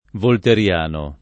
volteriano [ volter L# no ] → volterriano